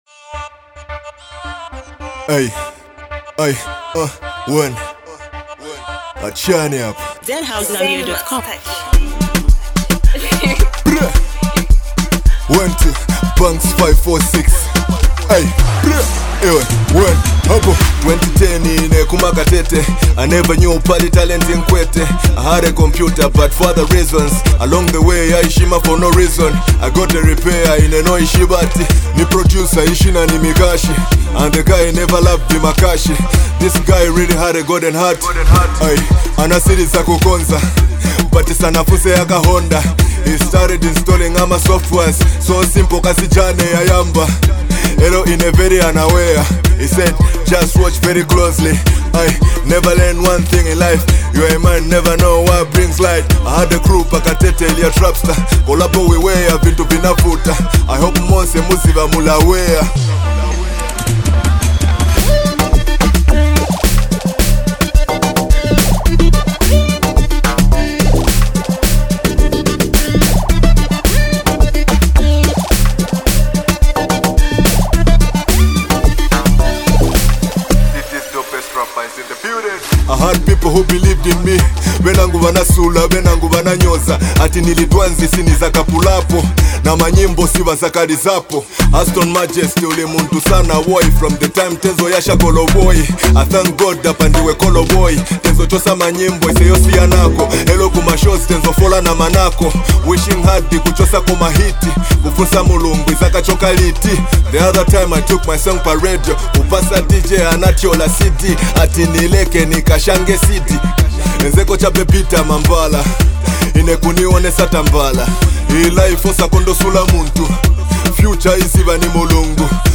With emotional lyrics and raw energy